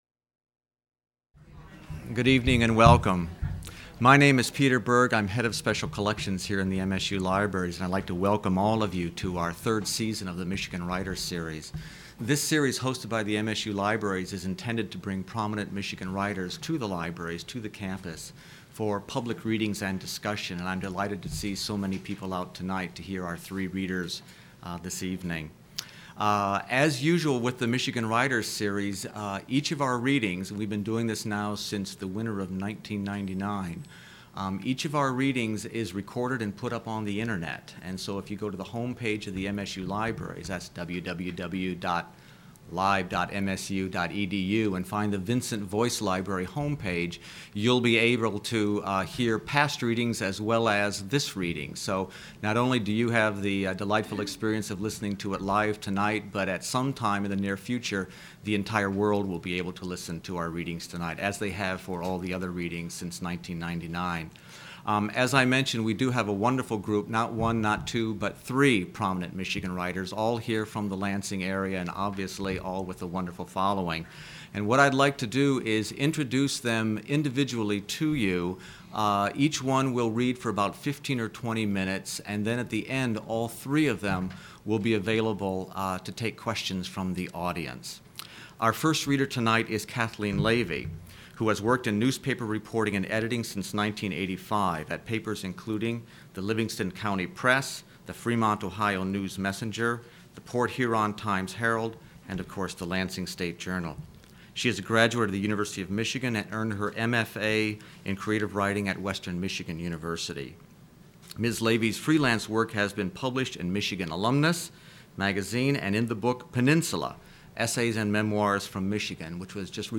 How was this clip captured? at the Michigan Writers Series